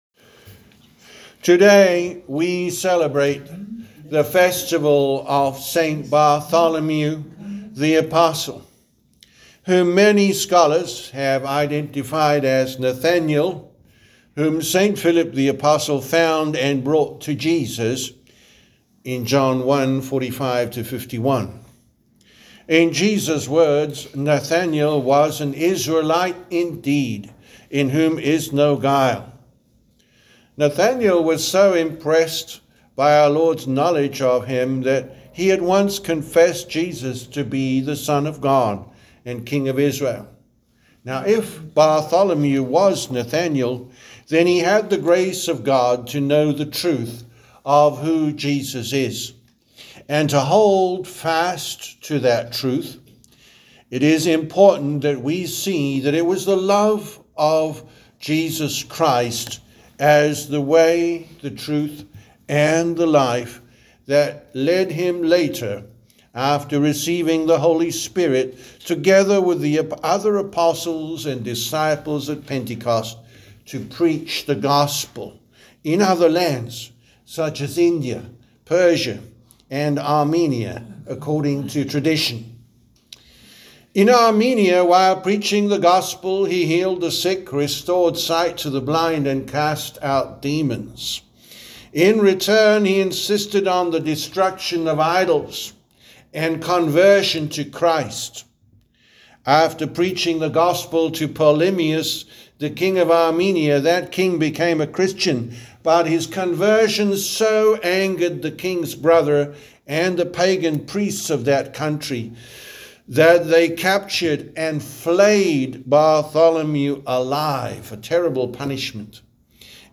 The Sermon for Sunday, August 24th, 2025, the Festival of St. Bartholomew the Apostle